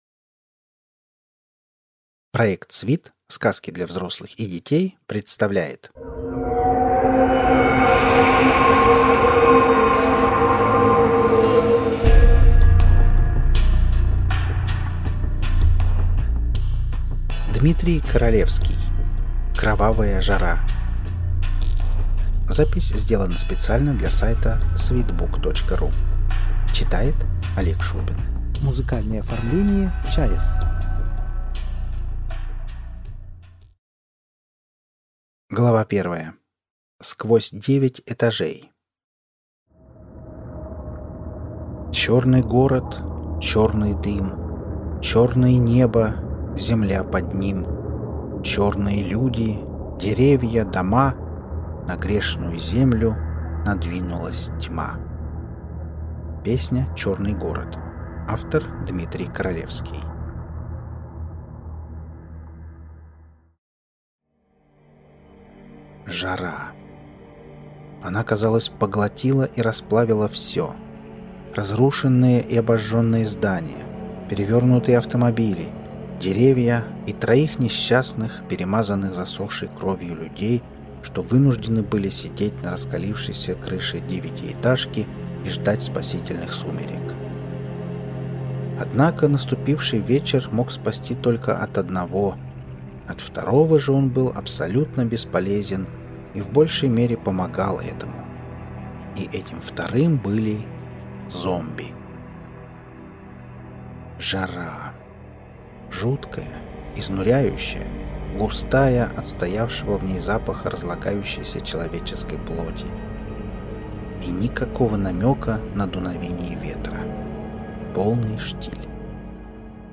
Аудиокнига Апокалипсис с небес. Кровавая жара. Ледяные трущобы | Библиотека аудиокниг